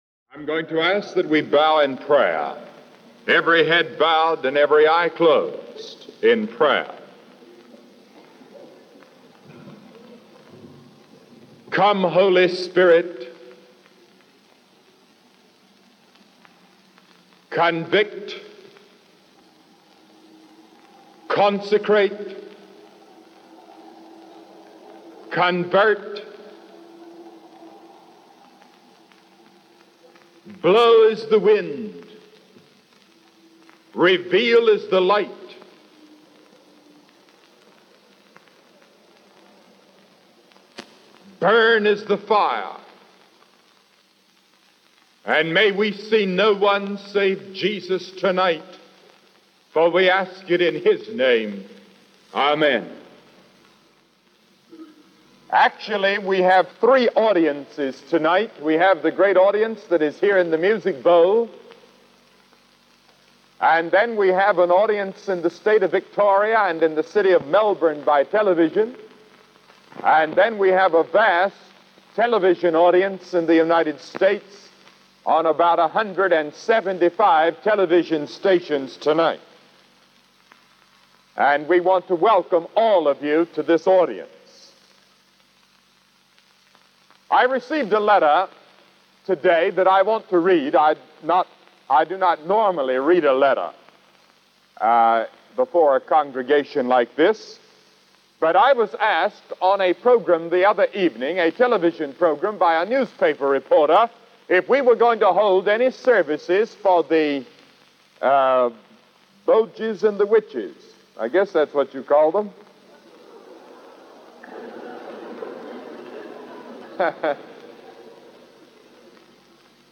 ‘I am the truth.’ Listen as Billy Graham explains why these words spoken by Jesus Christ are so powerful and important to our lives in the message he delivered in Melbourne, Australia in 1959.